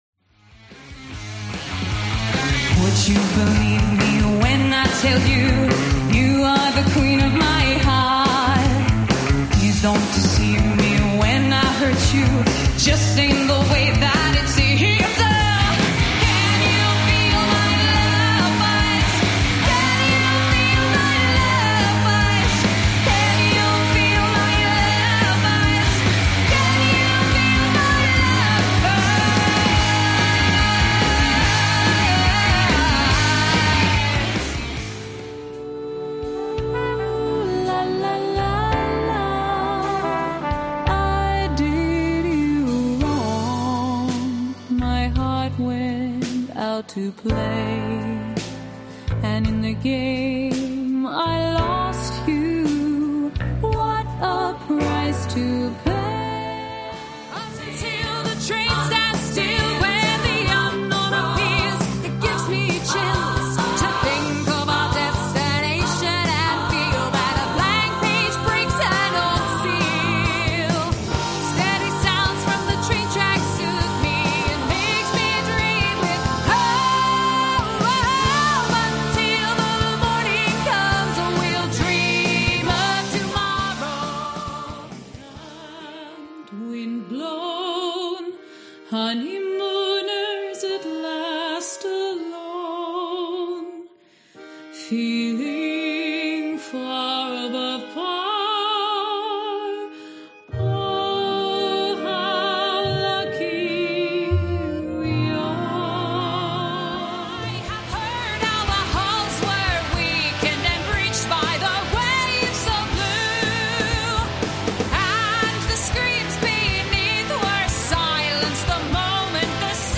Singing Showreel
Her energetic enthusiasm and clarity can be utilised for a variety of tones from bubbly and youthful, to sultry and inviting, to friendly and charming and beyond.
Female
Neutral British
Bright
Playful
Warm